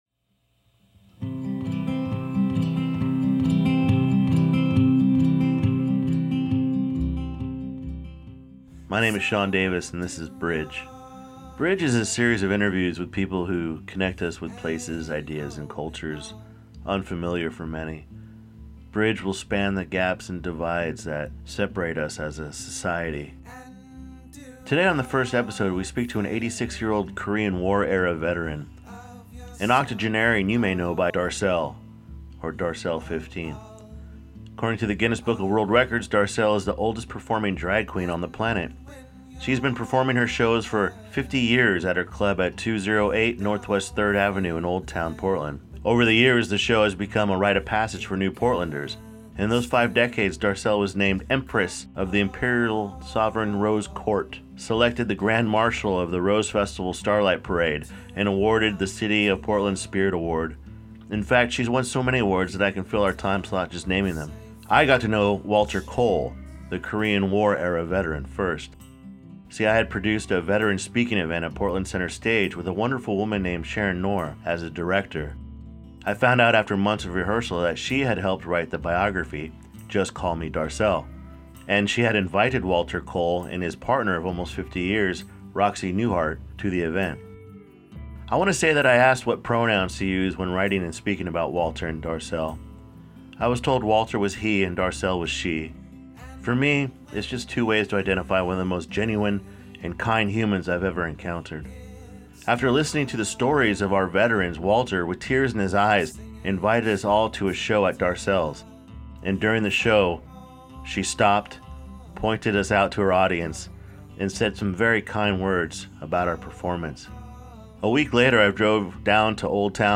My first interview is with Walter Cole, someone you may know as Darcelle XV.